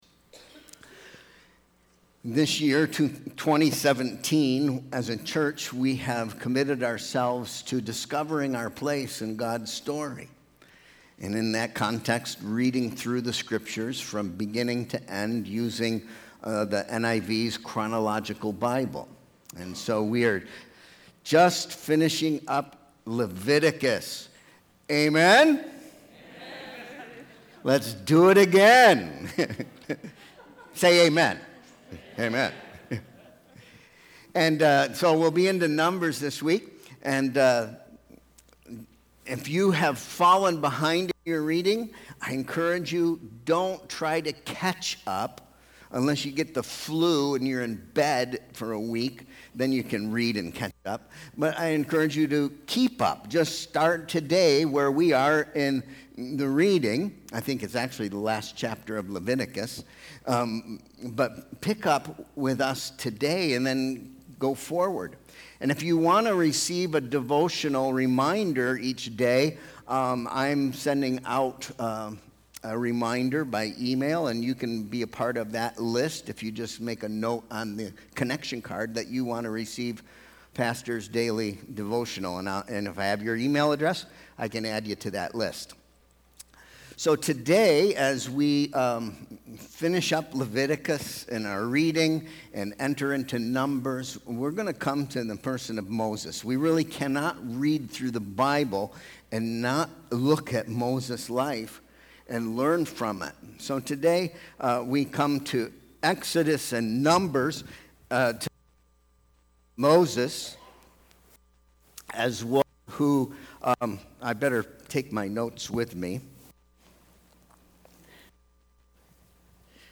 Sermon Question: What are the moments that will define your place in God’s story? Today we examine the story of Moses to discover how a life can be defined in the decisions of a moment.